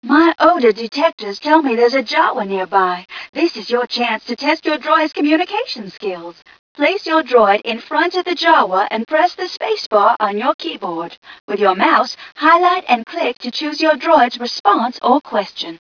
mission_voice_tgca024.wav